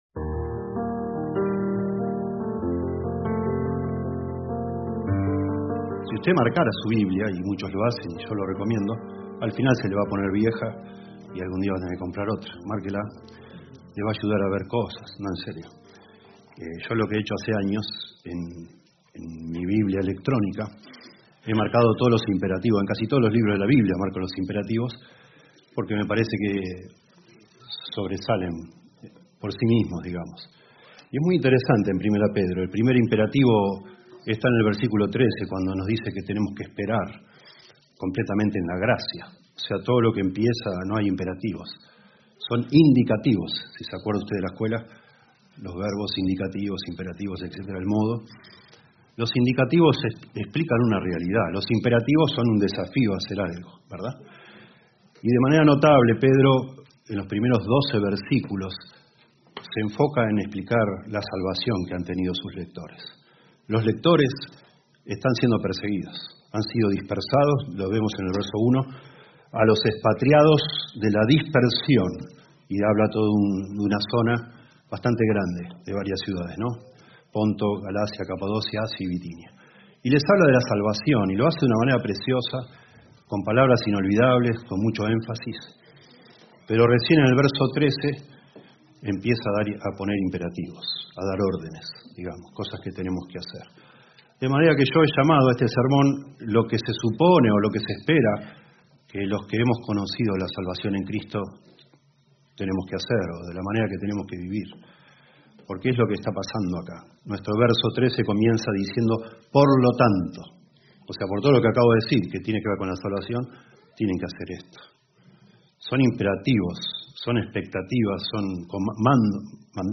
Reina-Valera 1960 (RVR1960) Video del Sermón Audio del Sermón Descargar audio Temas: Santificación